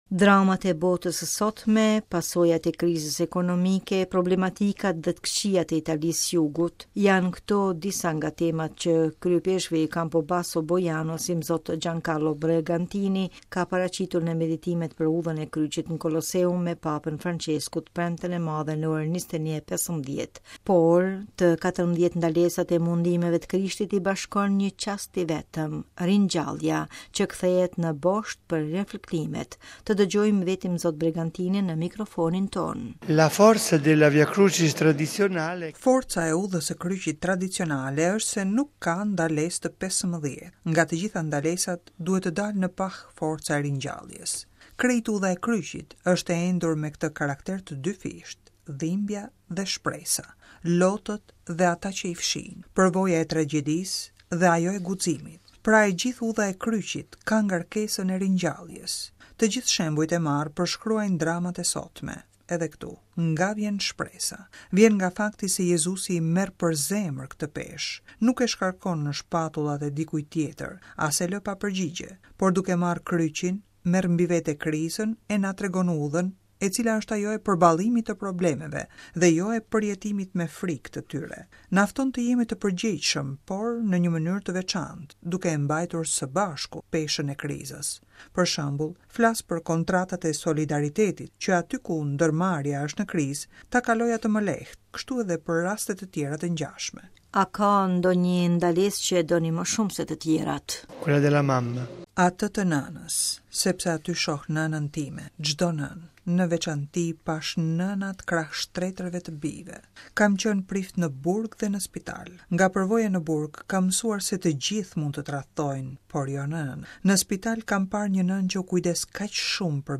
Të dëgjojmë vetë imzot Bregantinin, në mikrofonin tonë:RealAudio